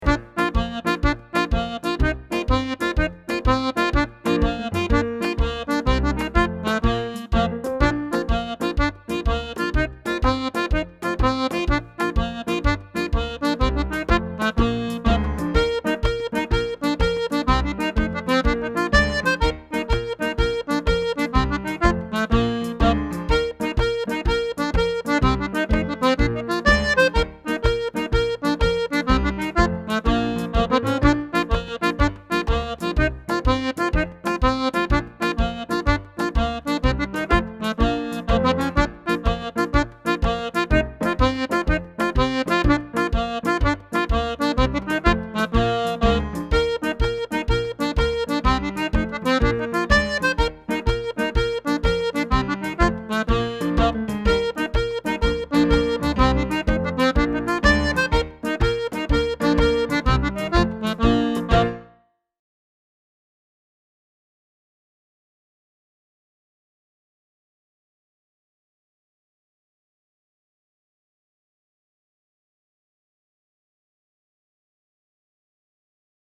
Single Jigs